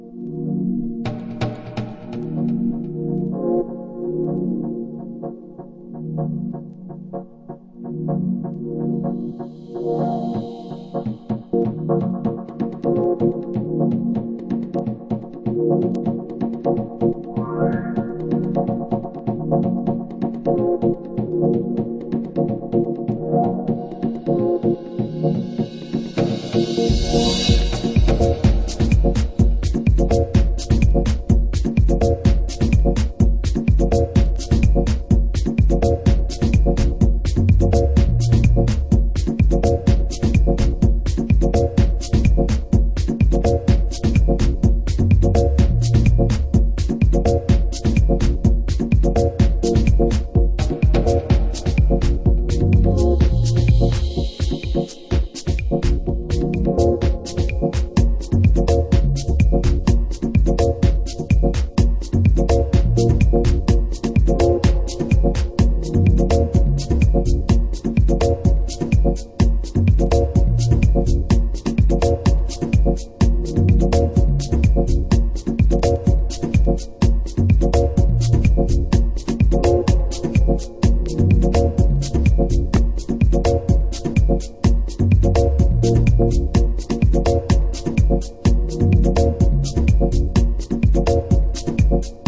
Beautiful, deep, Rhodes laden track on this one sided disc.